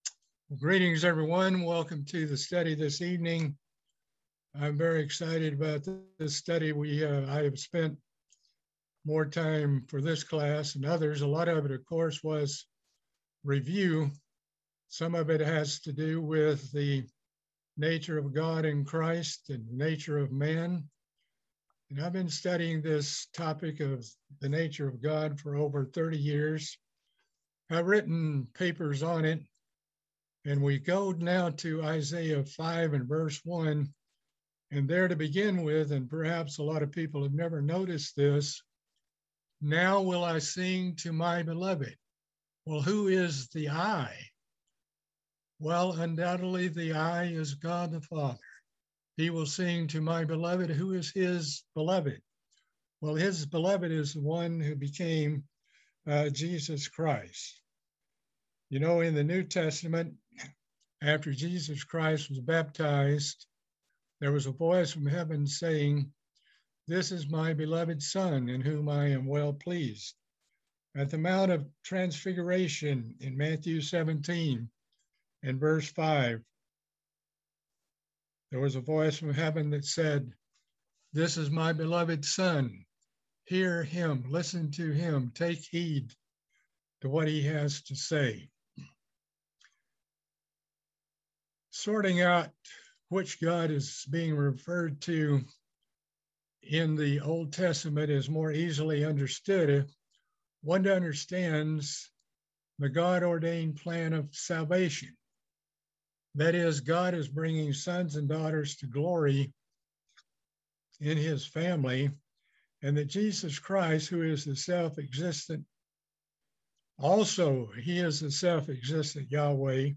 A continuing Bible Study series on the book of Isaiah.